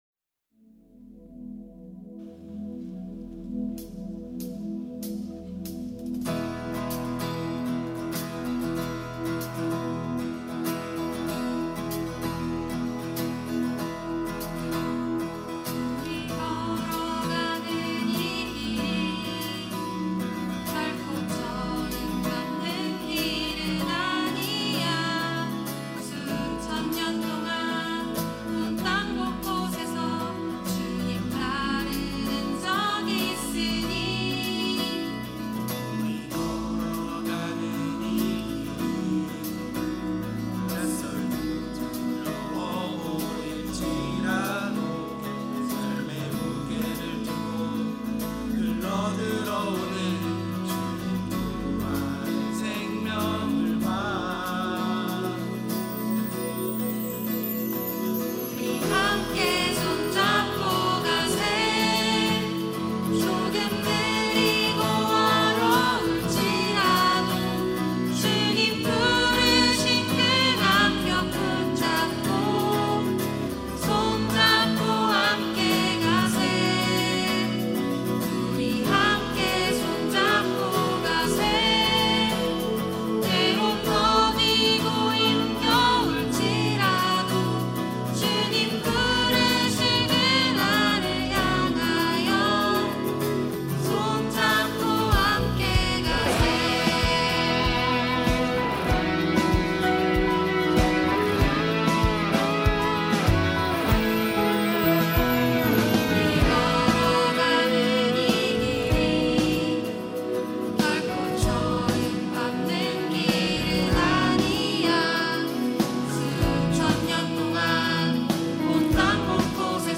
특송과 특주 - 손 잡고 함께 가세